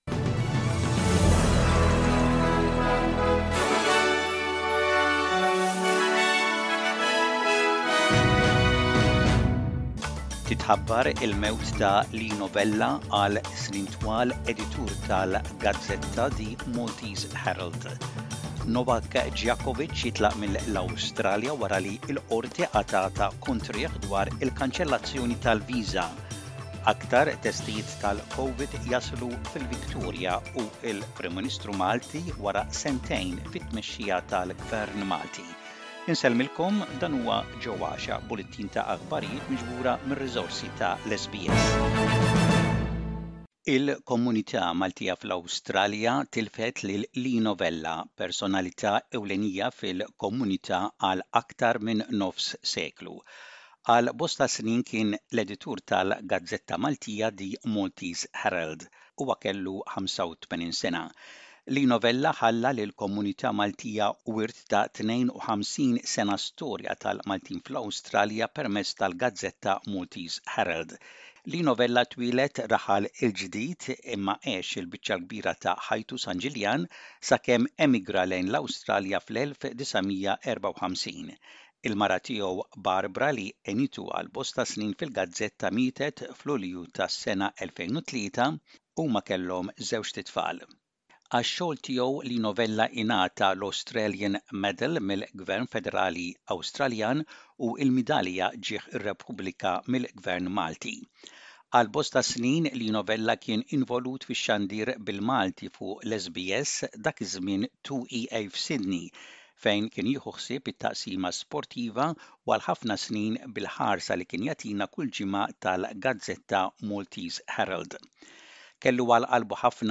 SBS Radio | Maltese News: 18/01/22